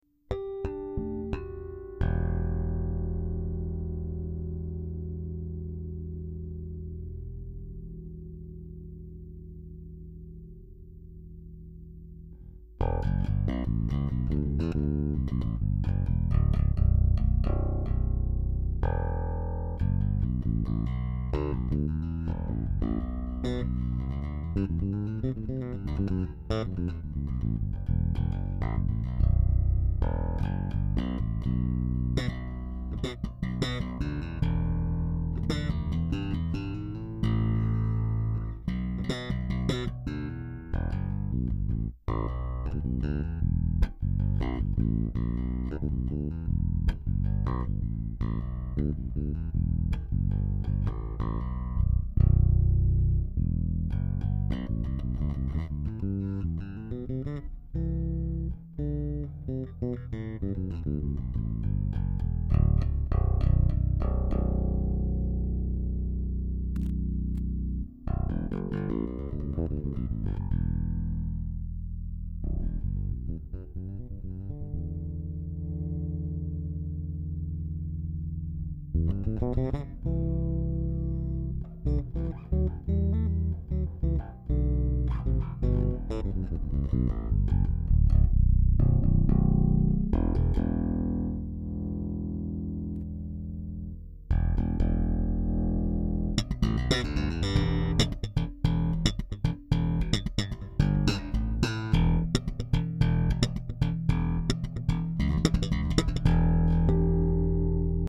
Bass KSD Ken Smich designer. sound effects free download
Circuito eletrônicco jhon east passivo/ attivo Bass KSD Ken Smich Designer.